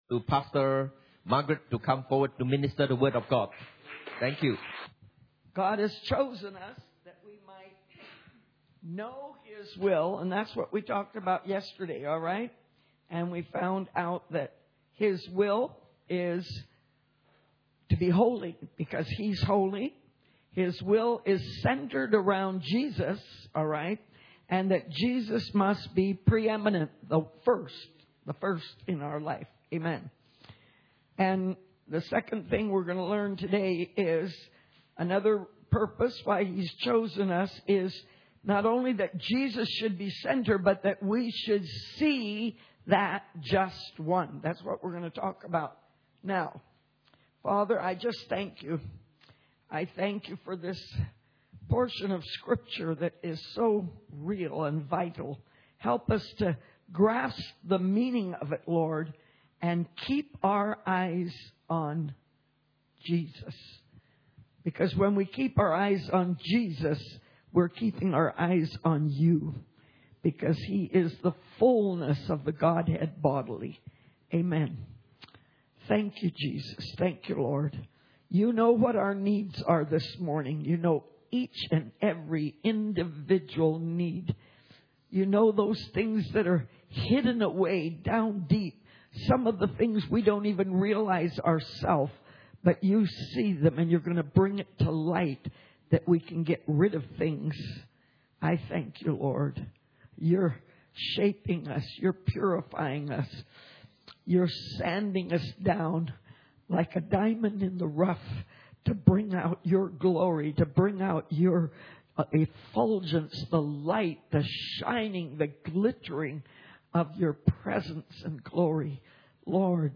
Church Camp 2012 Session 4 – Jesus is the Just One